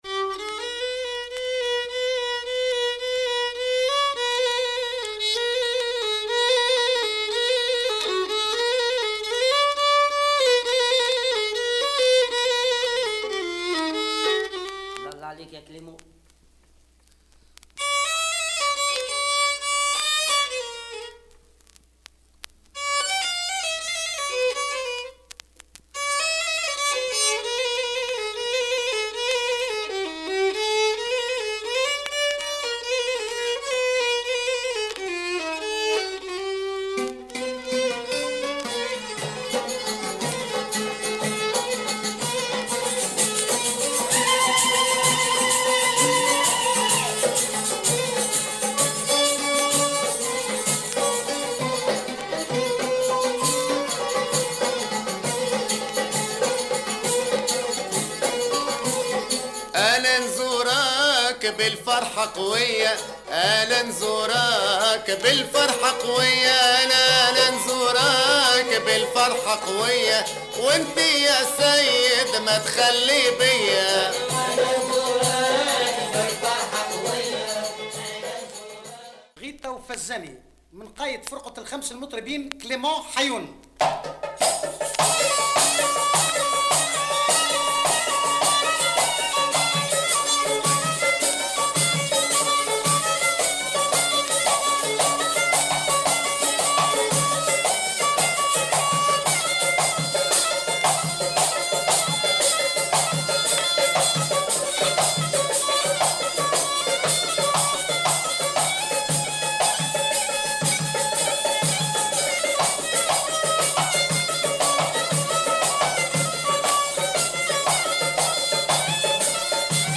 Deep Jewish Tunisian folk music